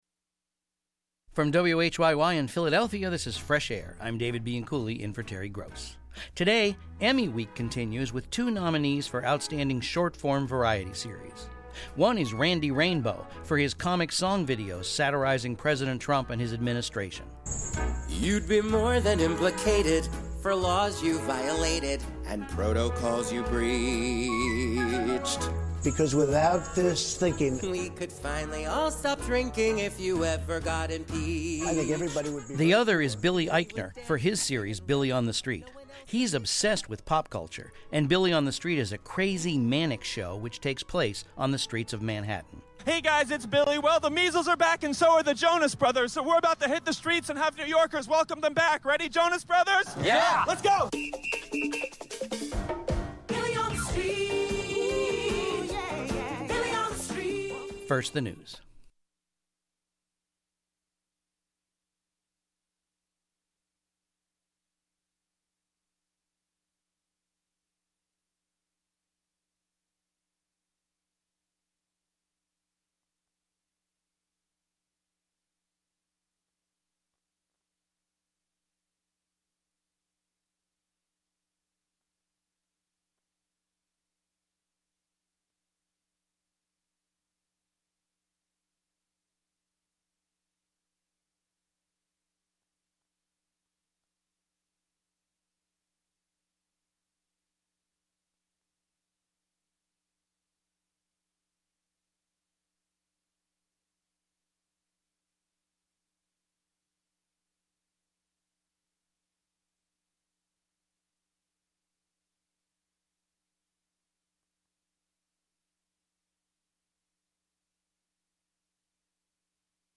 Interview Stephen.